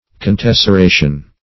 Contesseration \Con*tes`ser*a"tion\, n.